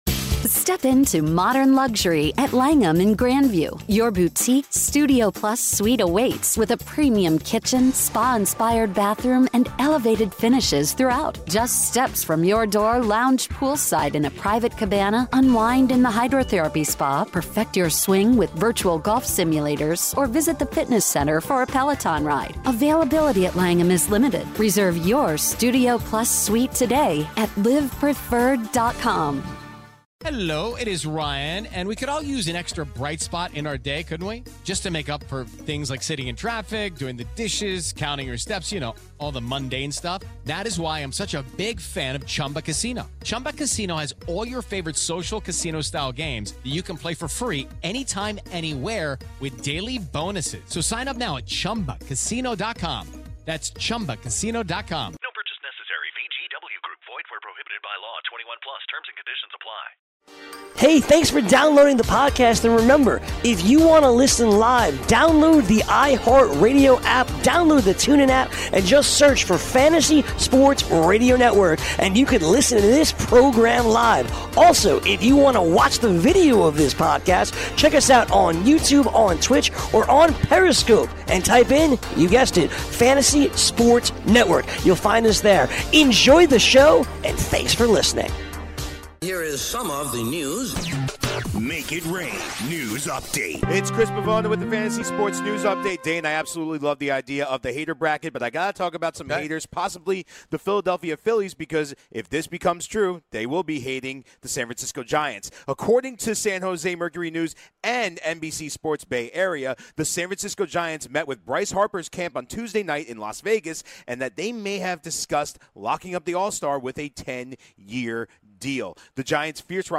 The guys open the final hour of the show giving a quick glimpse at the Haters Bracket.